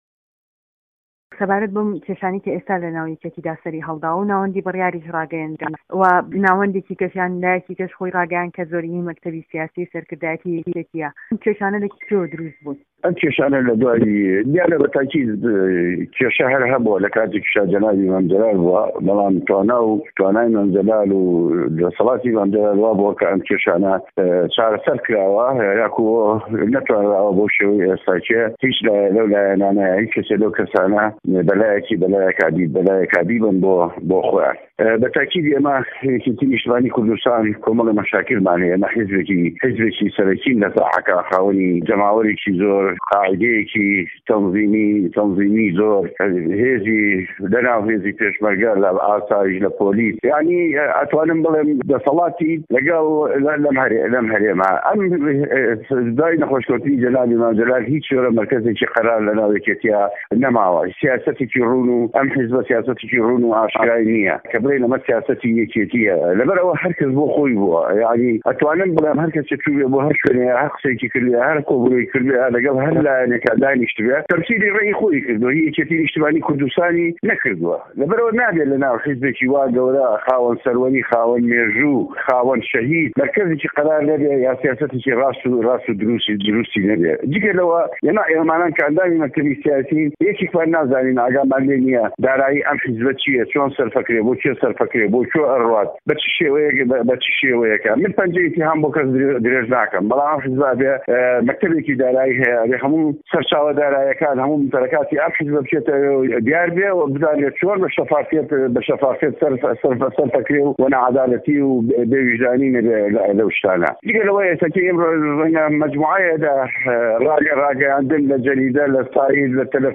وتووێژ لەگەڵ شێخ جەعفەر مستەفا